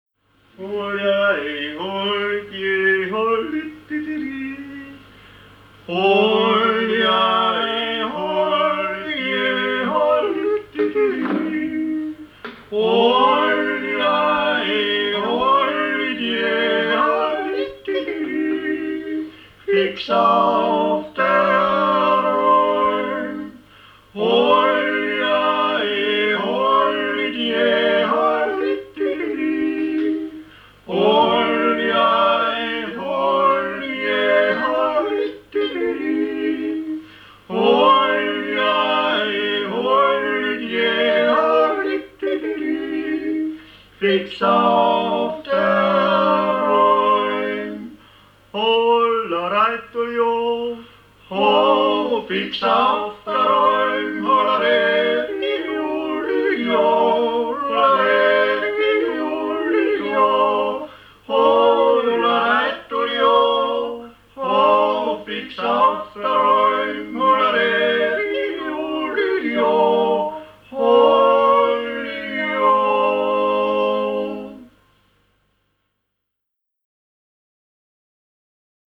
CD 1_Titel 14: WeXel oder Die Musik einer Landschaft Teil 2.1 - Das Weltliche Lied - Ungeradtaktig: Jodler und Jodler-Lied – Ungeradtaktig (E-BOOK - o:1625)